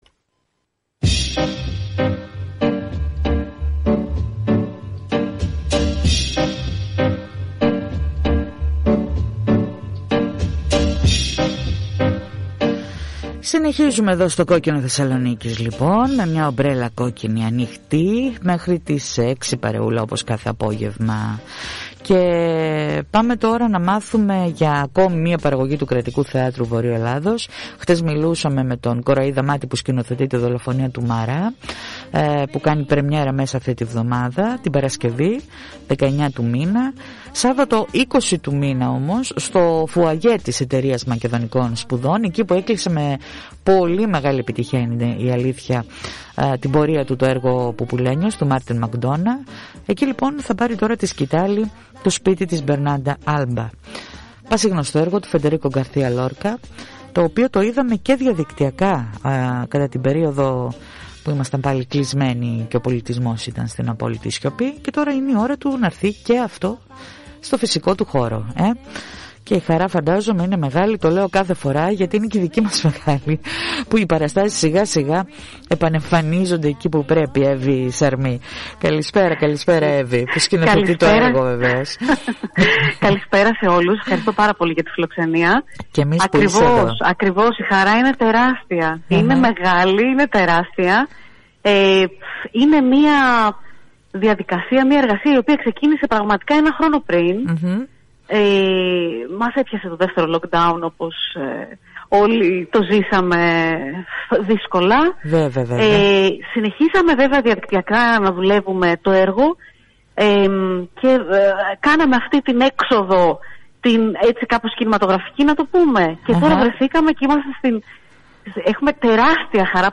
Ακούστε την συνέντευξη Μετά το lock down και την κινηματογραφική προβολή η δια ζώσης πρεμιέρα Η χαρά είναι τεράστια για την επανεμφάνιση των παραστάσεων στον φυσικό τους χώρο.